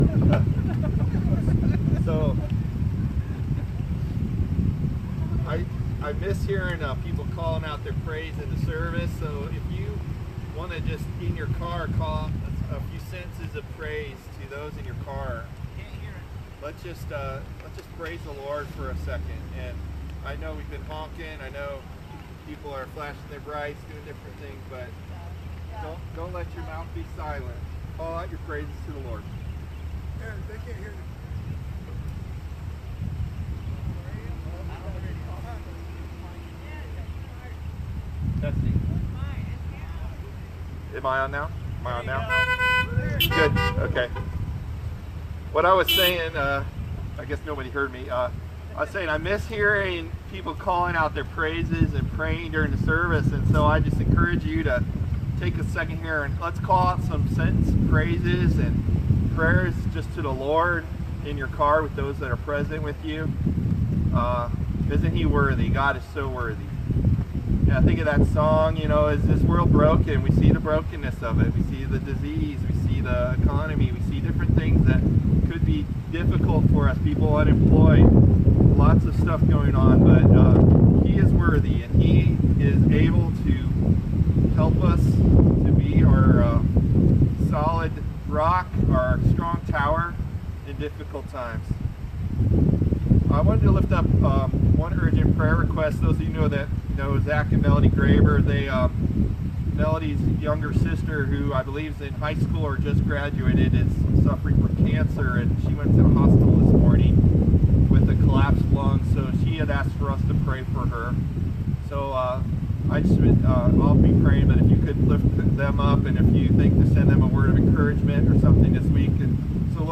We had another drive-in Sunday worship service. It was a bit chilly wit the wind, but we powered through.